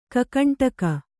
♪ kakaṇṭaka